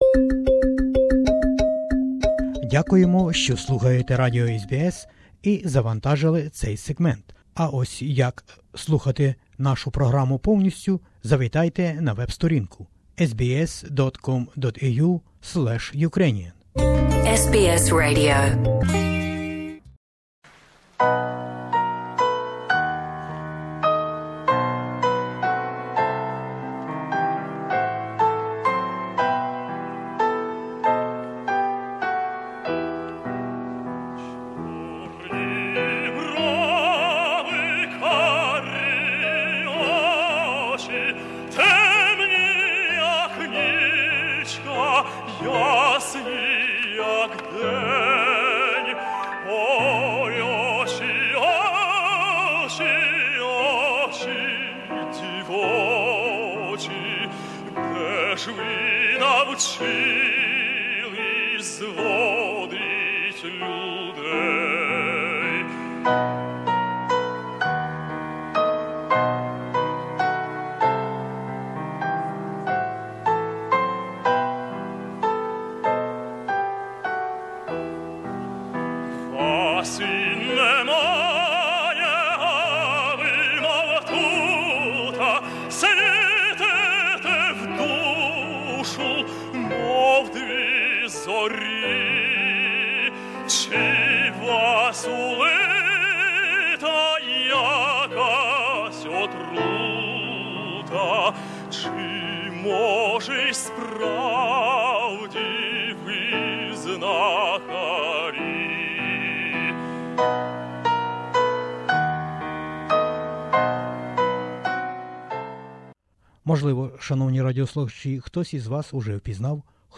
interviewed the Ukrainian tenor